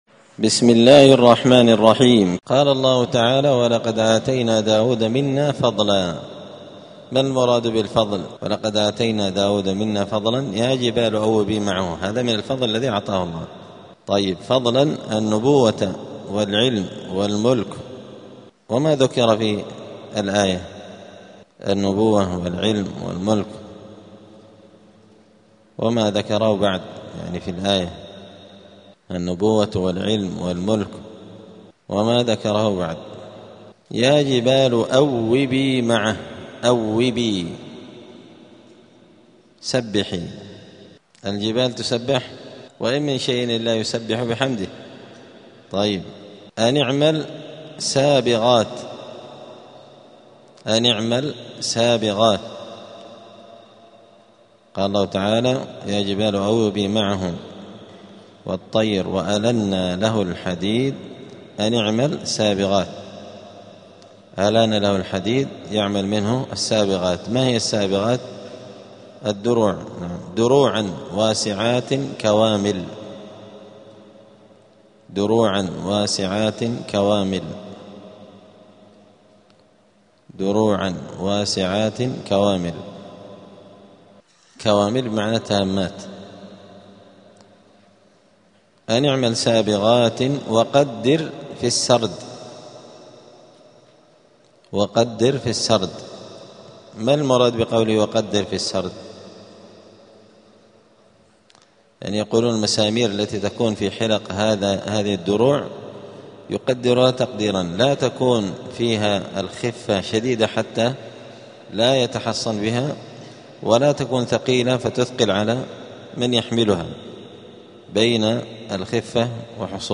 الثلاثاء 24 رجب 1447 هــــ | الدروس، دروس القران وعلومة، زبدة الأقوال في غريب كلام المتعال | شارك بتعليقك | 7 المشاهدات
دار الحديث السلفية بمسجد الفرقان قشن المهرة اليمن